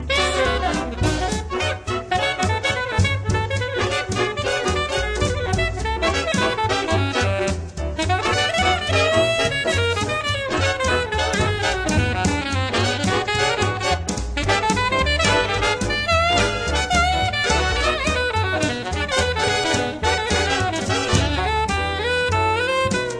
Recorded Eastcote Studios, West London 2006